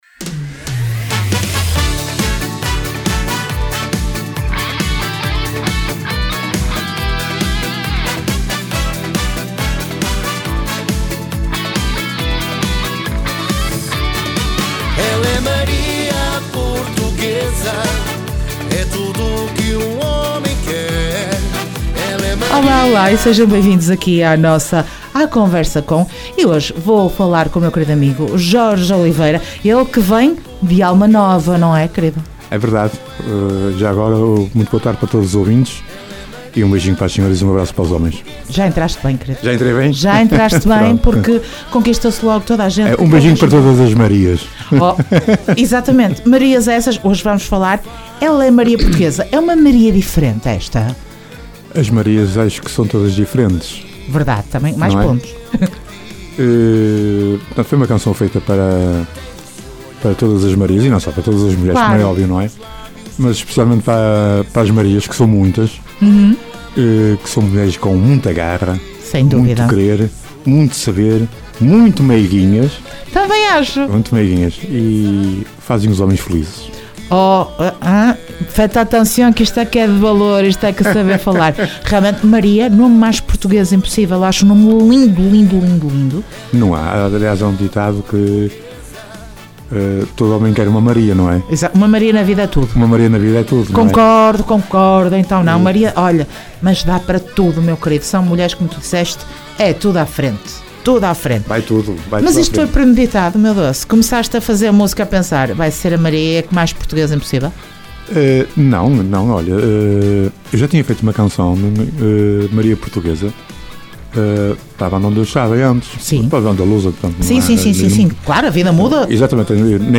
Entrevista Alma Nova dia 26 de março.